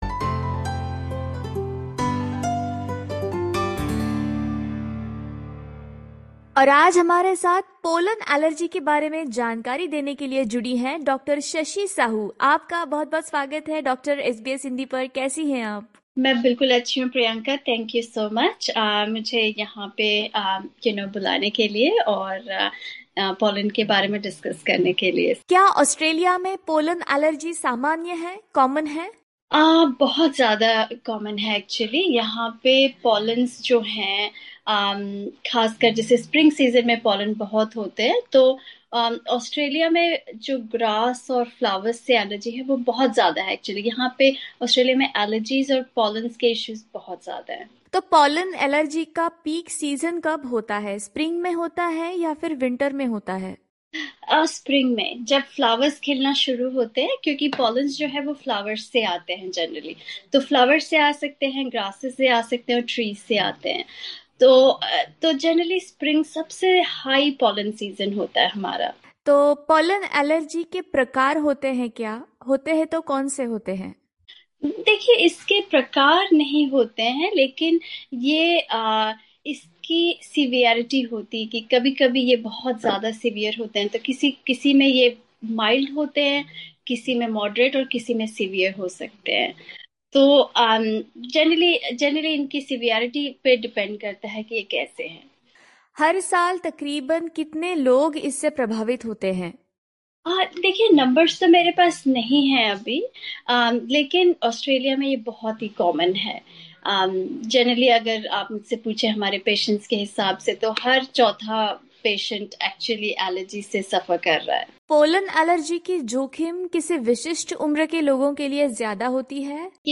बातचीत की।